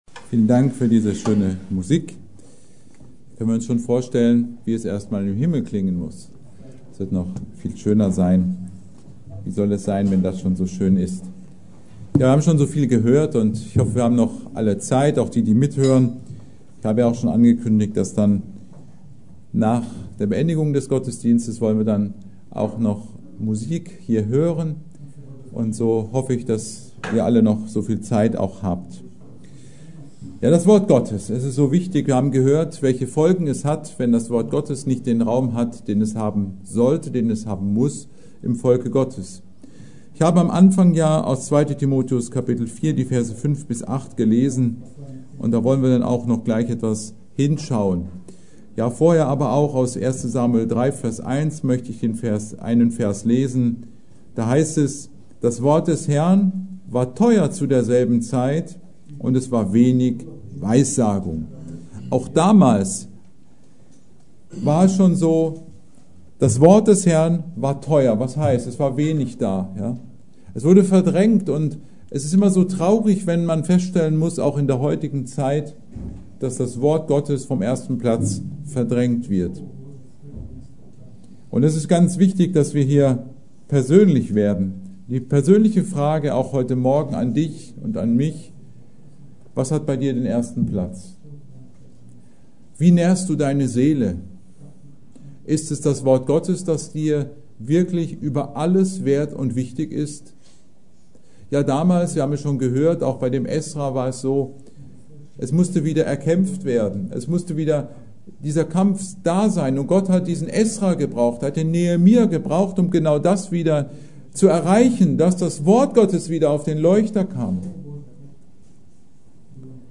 Predigt: Das Wort Gottes – auf dem ersten Platz in Deinem Leben?